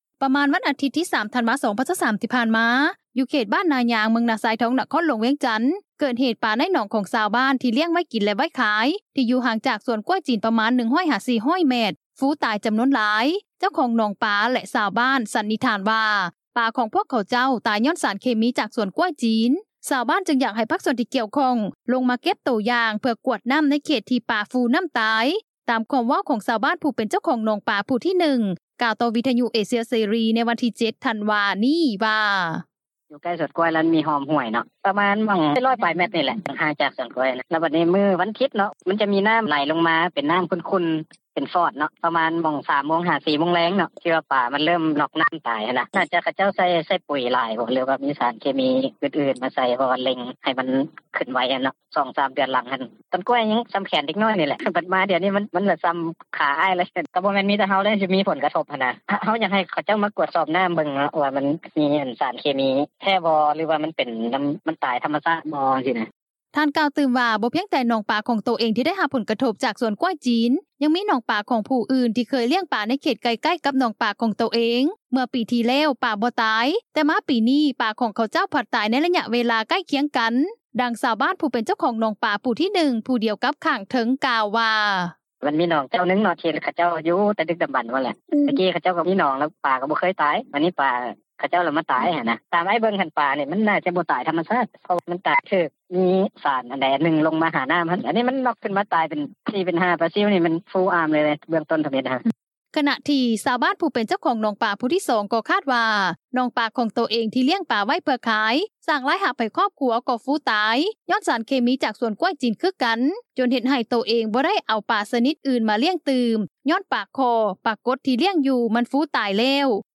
ດັ່ງຊາວບ້ານ ຜູ້ເປັນເຈົ້າຂອງໜອງປາ ຜູ້ທີ 1 ຜູ້ດຽວກັບຂ້າງເທິງ ກ່າວວ່າ:
ດັ່ງຊາວບ້ານ ຜູ້ເປັນເຈົ້າຂອງໜອງປາ ຜູ້ທີ 2 ກ່າວໃນມື້ດຽວກັນນີ້ວ່າ: